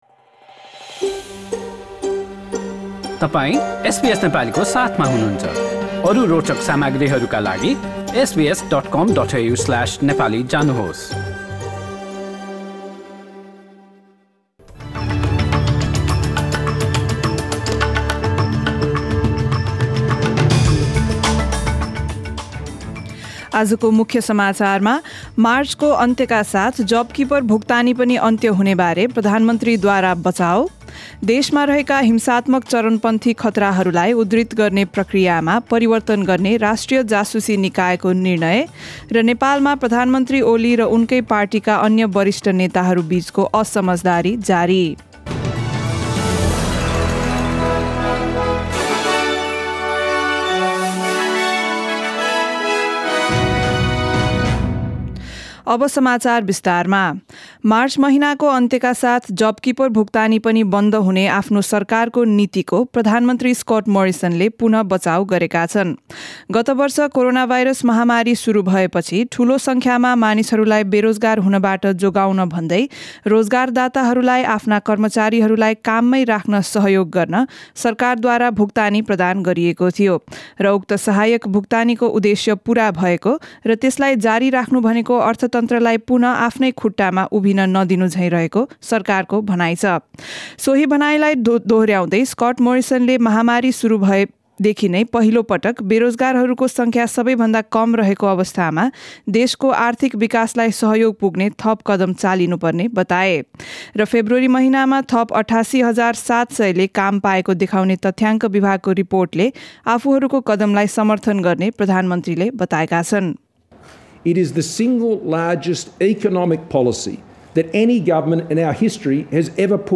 Listen to latest news headlines from Australia in Nepali.